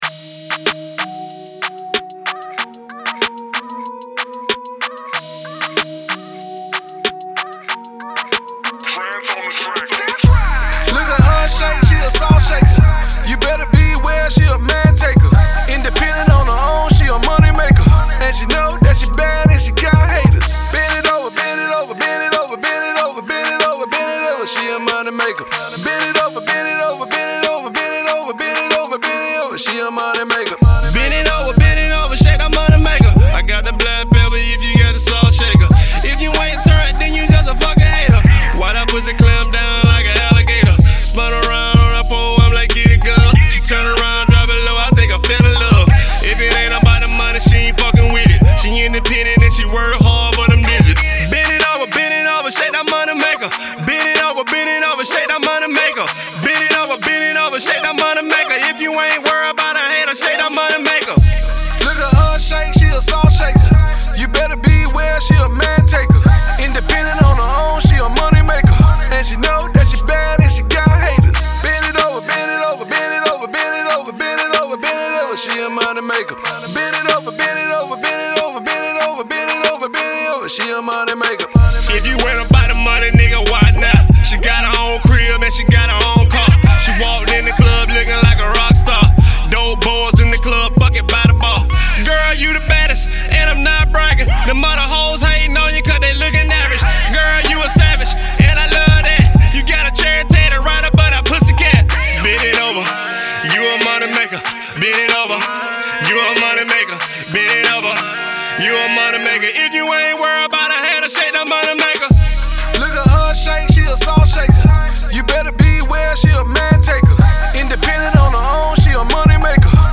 Rap
DJs you need this club banger